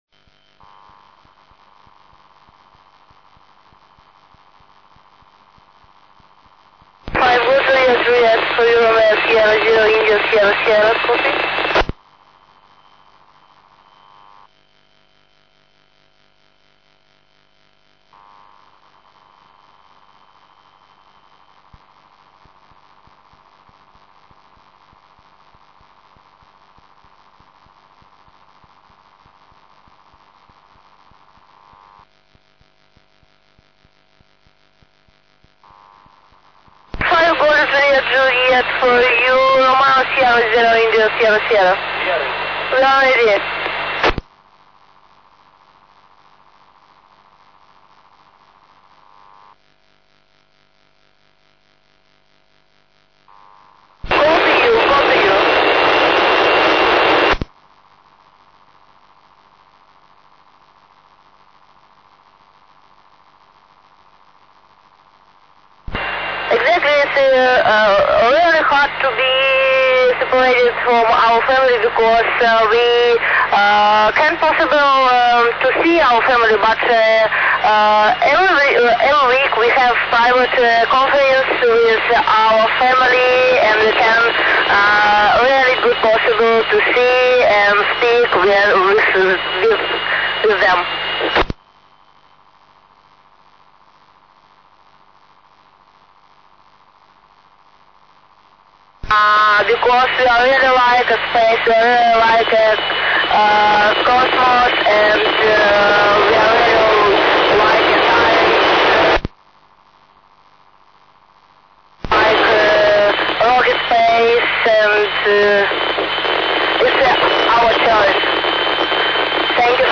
The Registration was done by using a simple Baofeng UV-3R transceiver and a vertical 5/8 lambda antenna placed on the balcony.